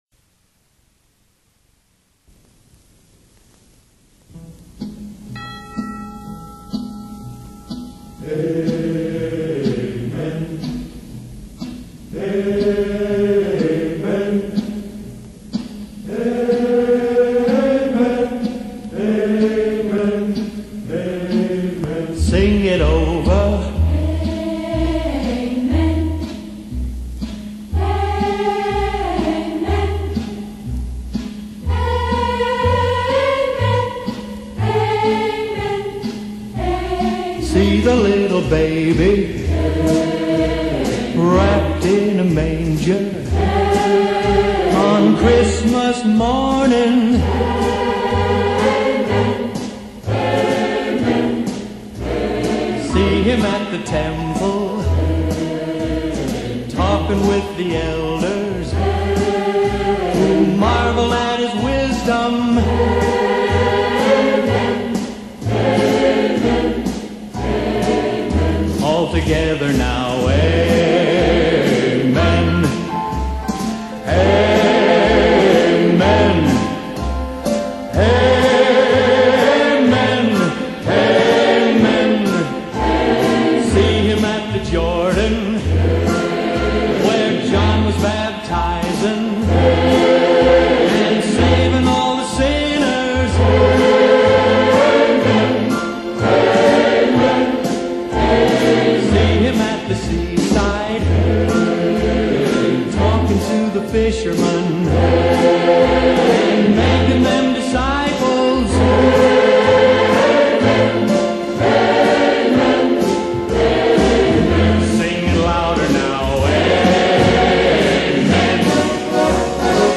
찬송, 복음송
보컬 그룹